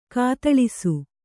♪ kātaḷisu